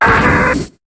Cri de Boskara dans Pokémon HOME.